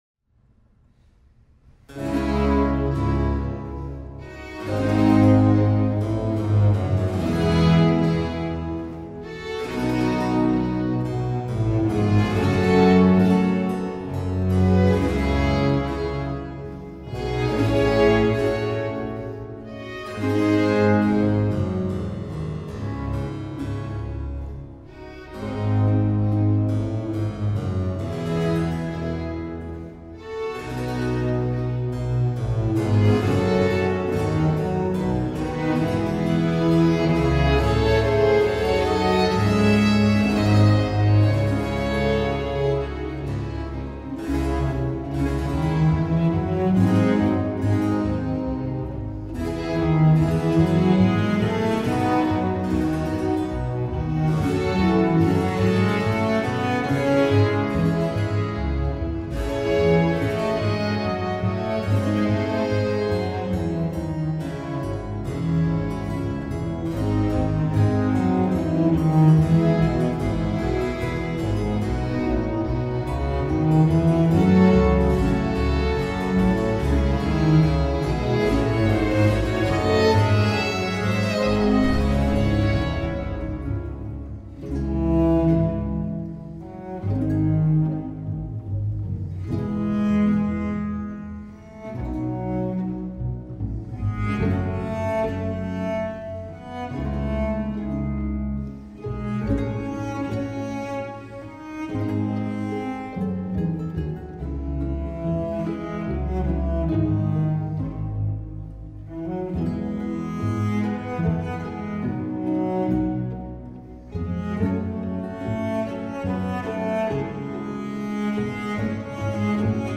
ساراباند اسم یک فرم موسیقایی مخصوص نوعی رقص سنگین و باوقار بوده است که این وقار کاملاً در ضرباهنگ‌های سنگین این فرم سه‌ضربی خودنمایی می‌کند.